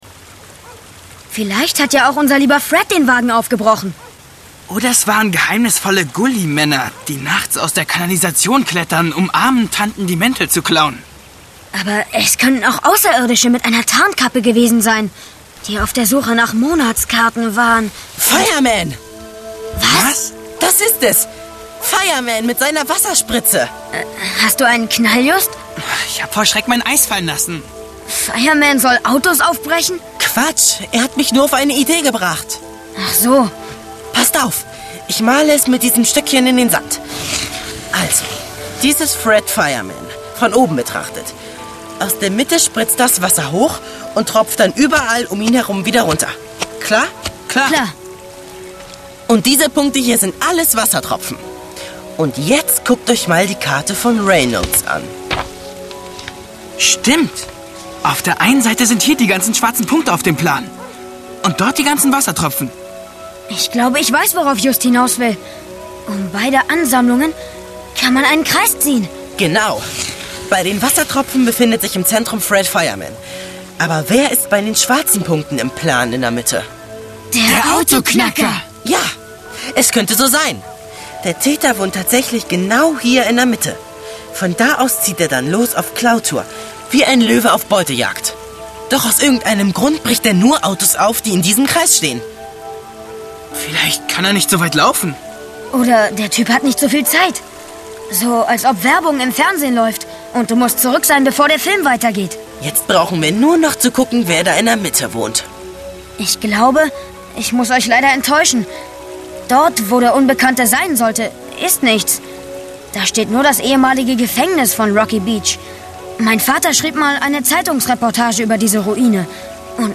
Die drei ??? Kids - Radio Rocky Beach | Physical CD Audio drama
DJ Wolfram - Sky du Mont
Aufnahme: Hastings, Hamburg - Hastings, Berlin -